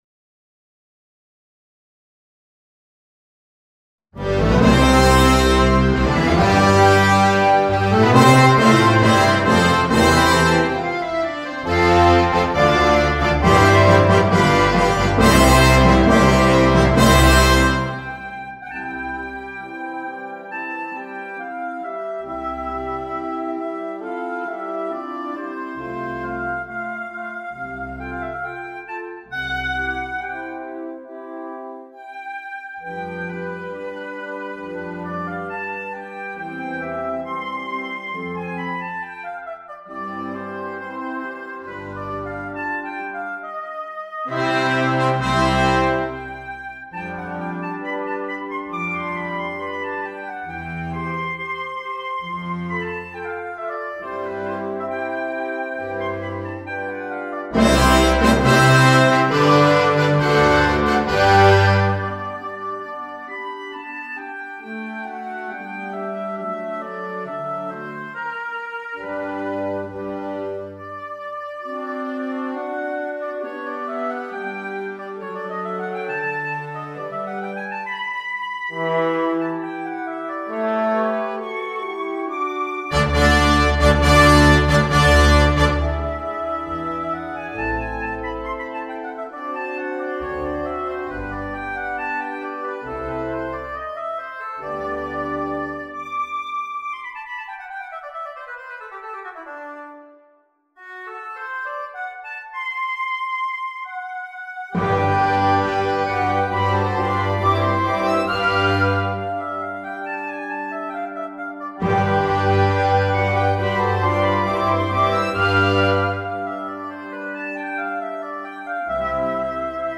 Per Oboe e Orchestra di Fiati
Trascrizione per Symphonic band